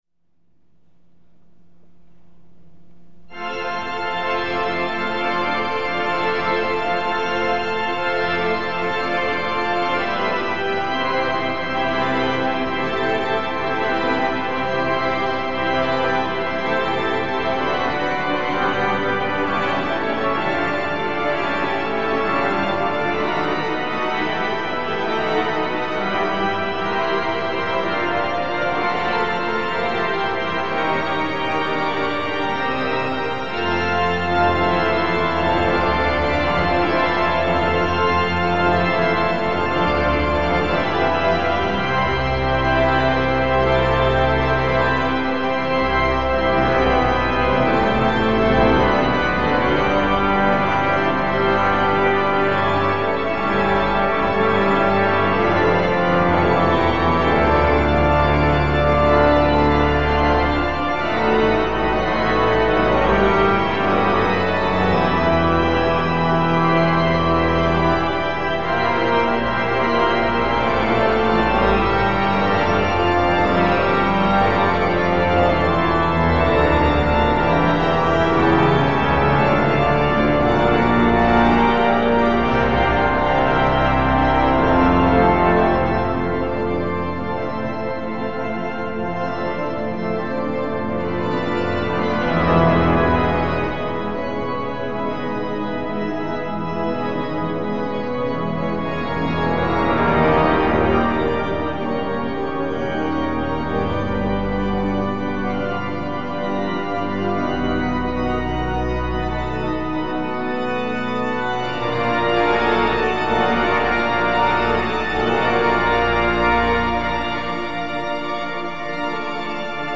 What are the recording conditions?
Tracks 3,7 recorded in Southwark Cathedral